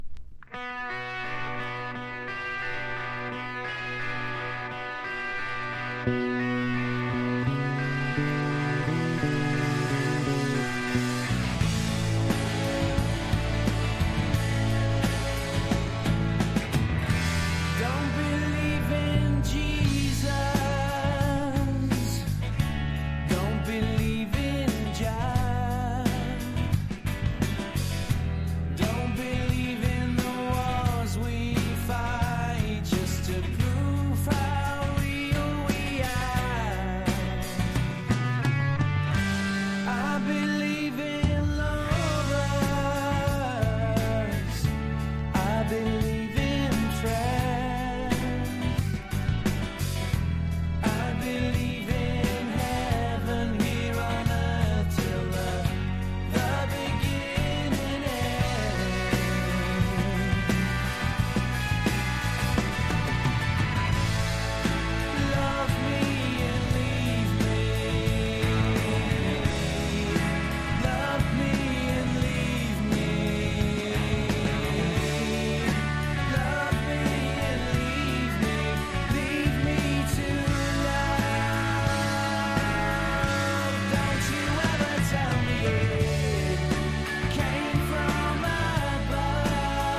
NEO ACOUSTIC / GUITAR POP (90-20’s)